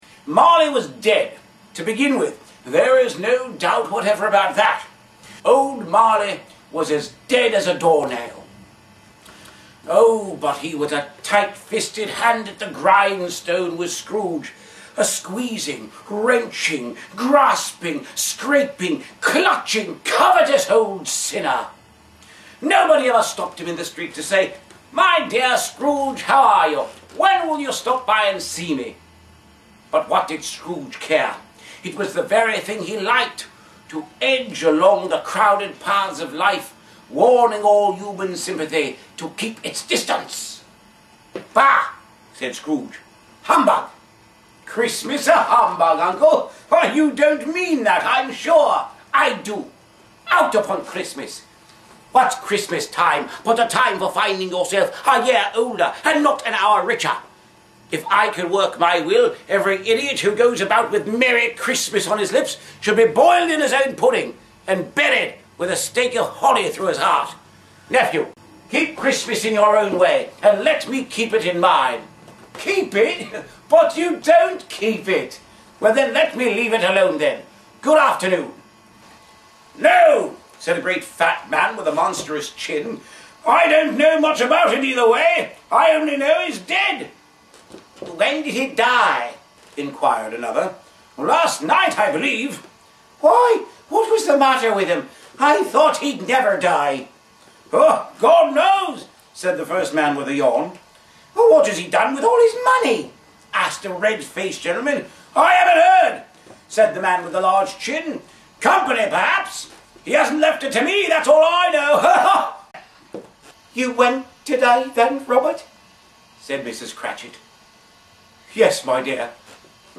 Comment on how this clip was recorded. Complete in costume and character, the staged reading is available in 45 and 90 minute versions.